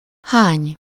Ääntäminen
IPA: /ʁɑ̃dʁ/